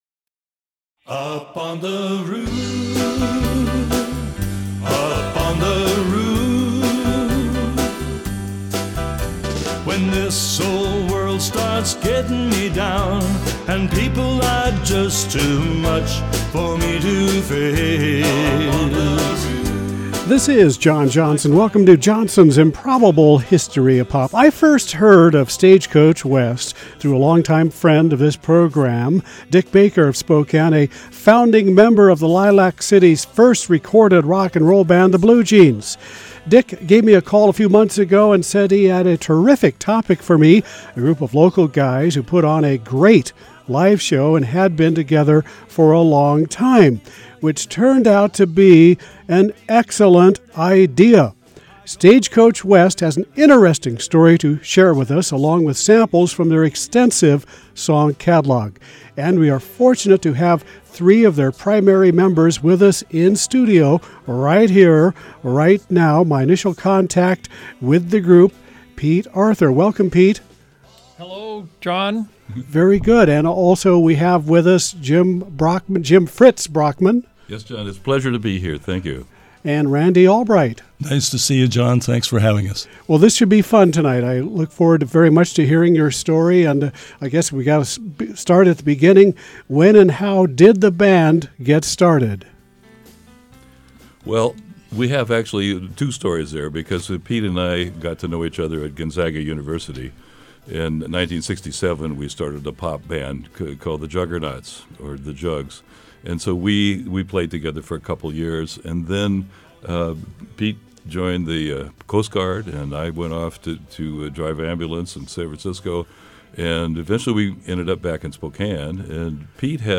KPBX Interviews #1 (Secrets Revealed!)